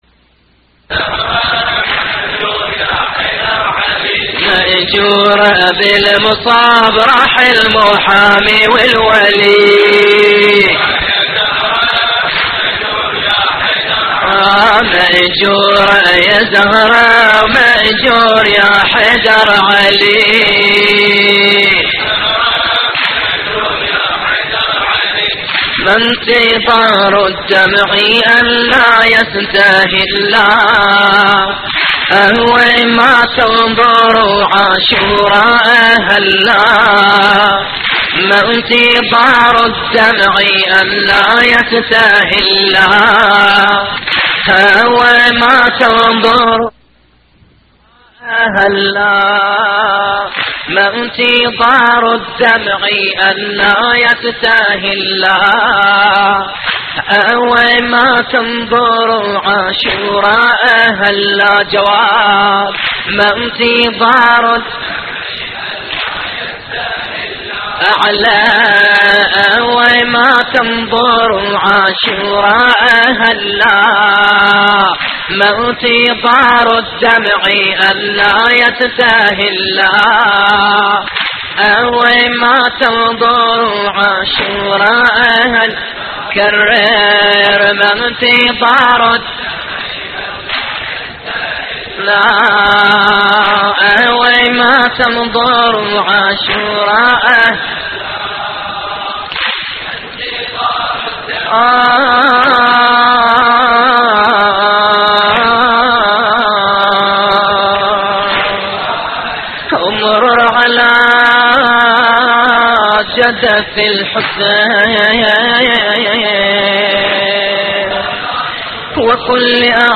موقع يا حسين : اللطميات الحسينية ما انتظار الدمع ان لا يستهل - لحفظ الملف في مجلد خاص اضغط بالزر الأيمن هنا ثم اختر (حفظ الهدف باسم - Save Target As) واختر المكان المناسب